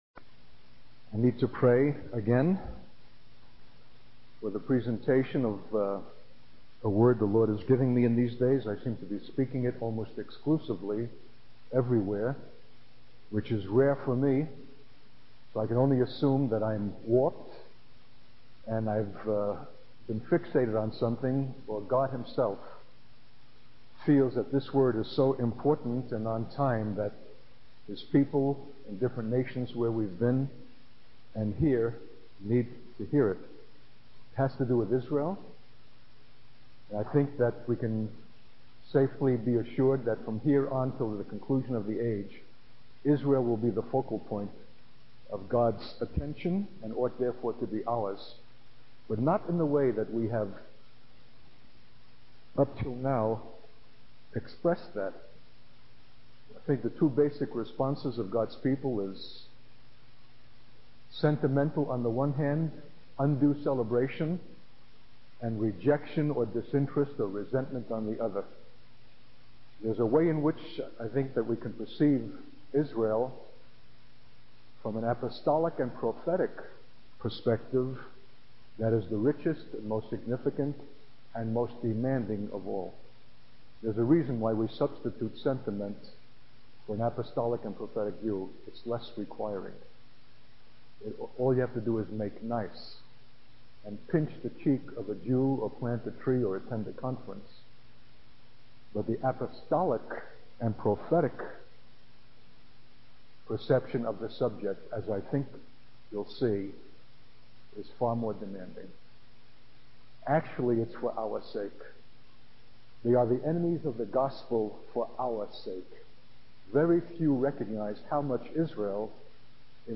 In this sermon, the speaker emphasizes the power and importance of the Word of God.